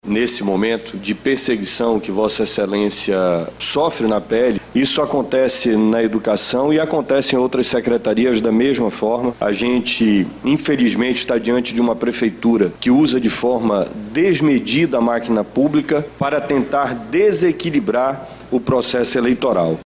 Os vereadores da Câmara Municipal de Manaus batem boca e trocam farpas durante sessão plenária nesta terça-feira, 27.